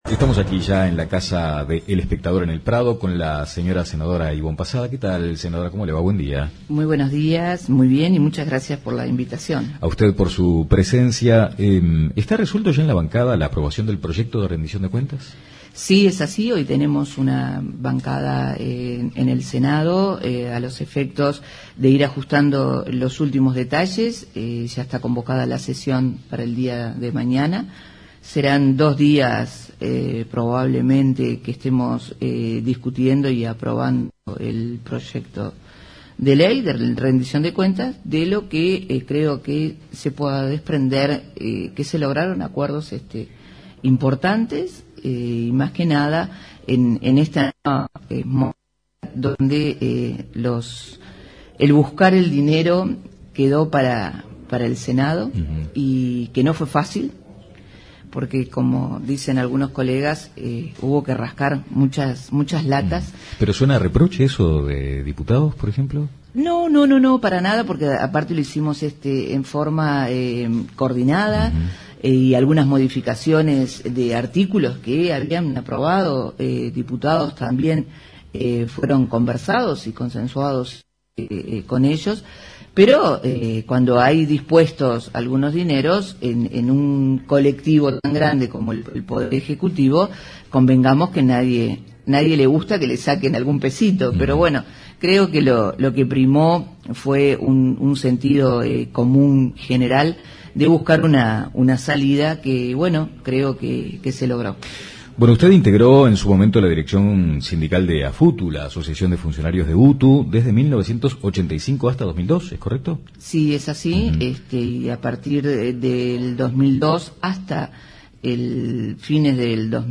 Entrevista a Ivonne Passada